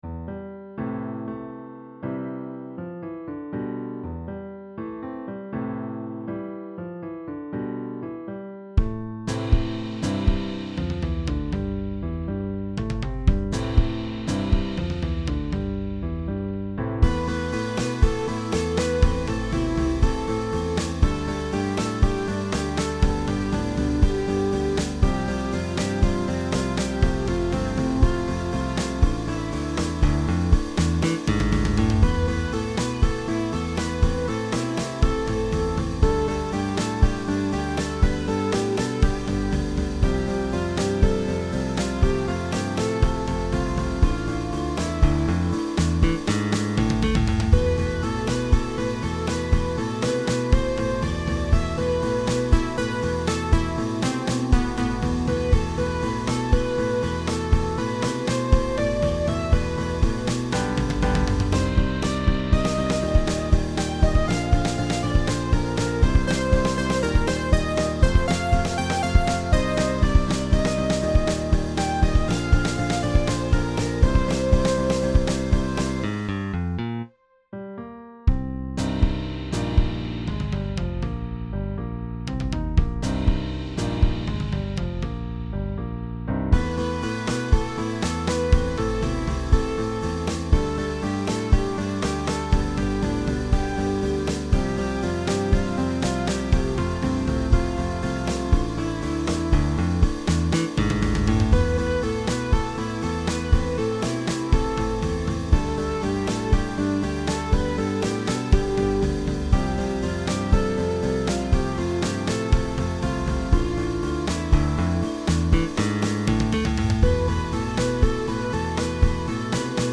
보컬 파트를 위한 신디사이저